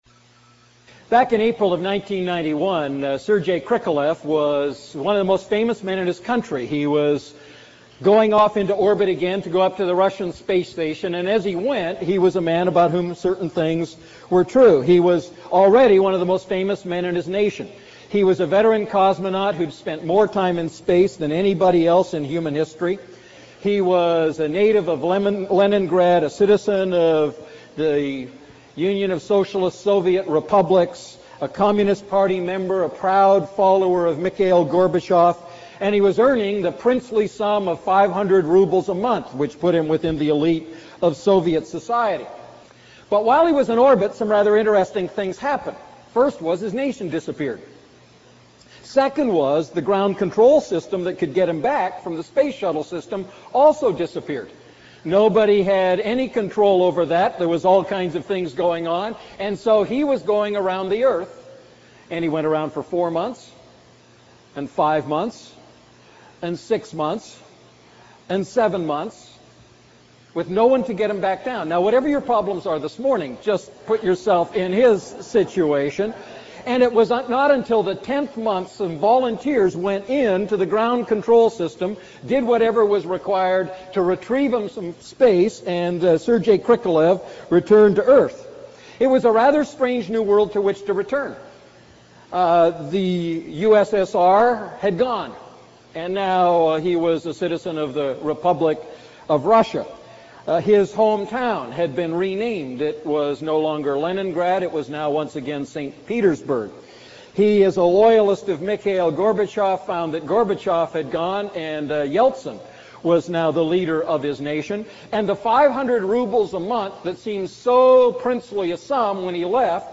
A message from the series "Wise Walking."